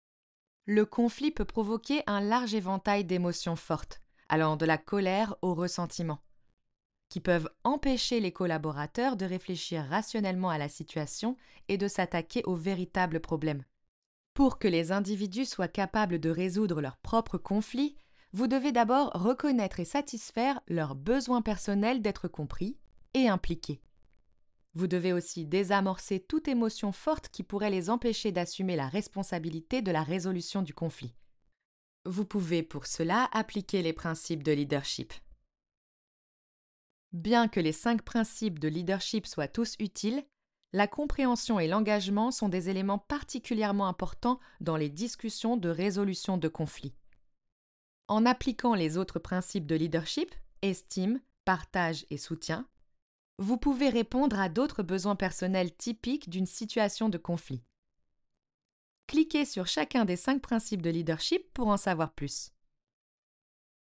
Voix off
8 - 44 ans - Mezzo-soprano